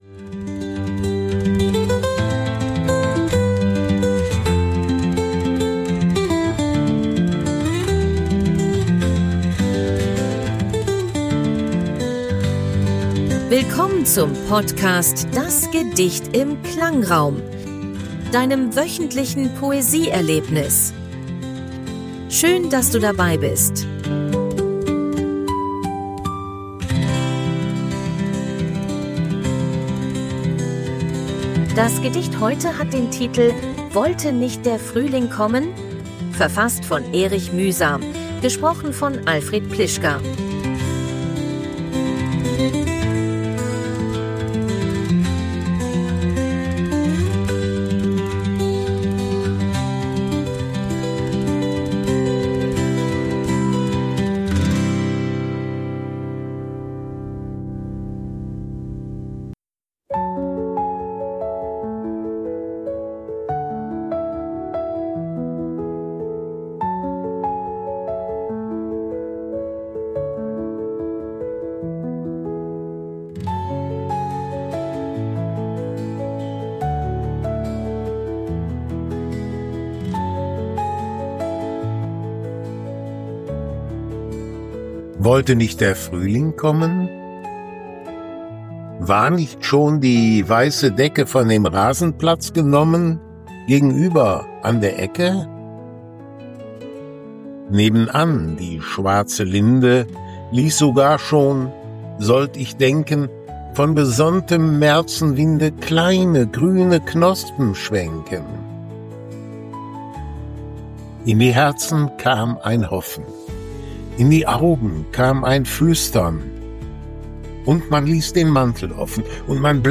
begleitet von KI-generierter Musik.